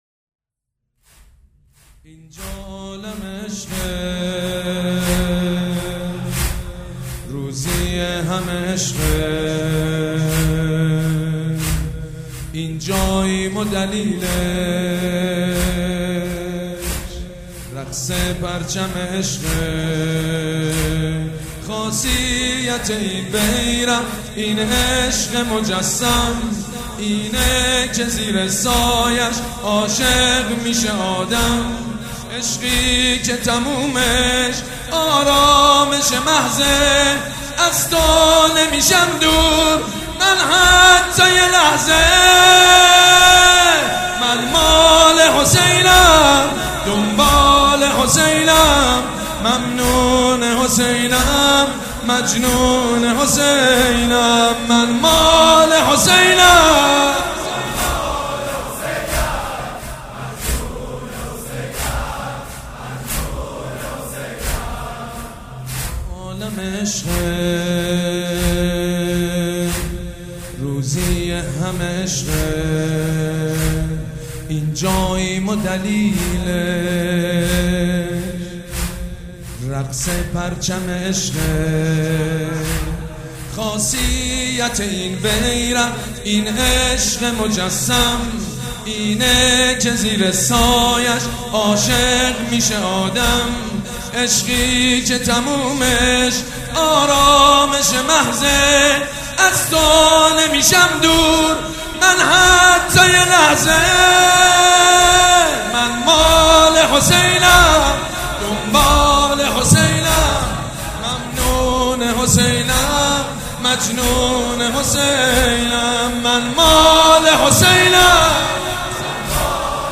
مداح
حاج سید مجید بنی فاطمه
مراسم عزاداری شب چهارم